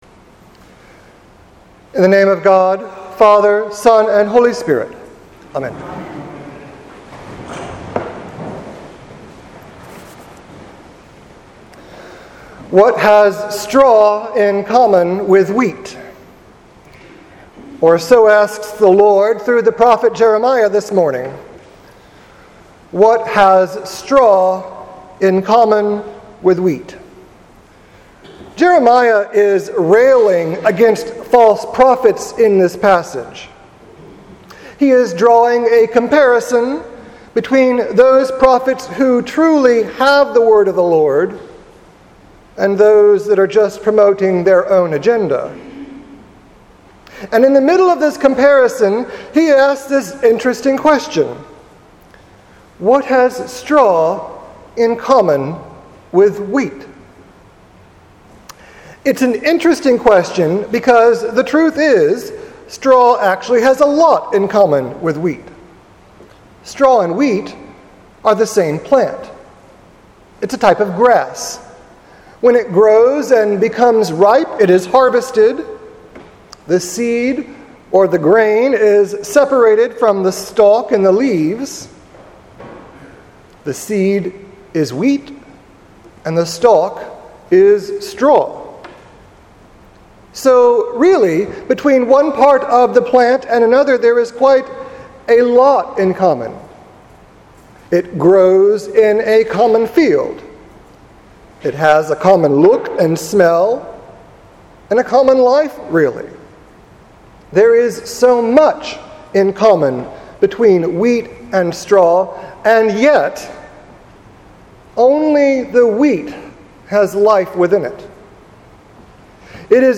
Sermon for the Thirteenth Sunday after Pentecost